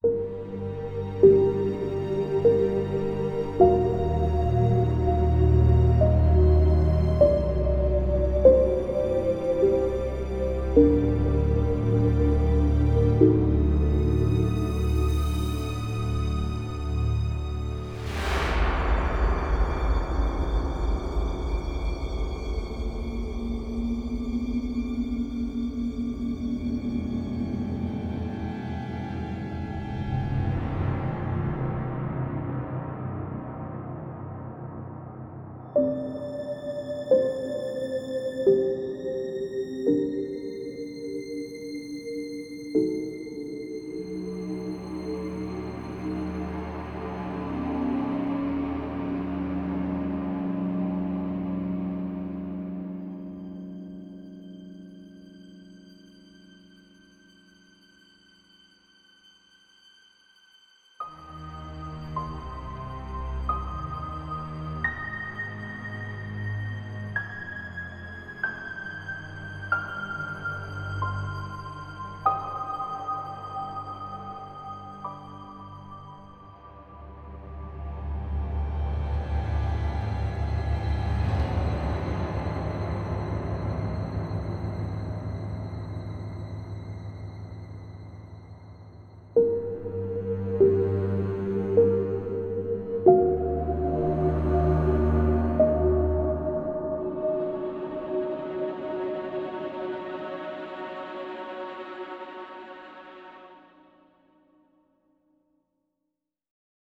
HORROR/SPOOKY
Ambient horror music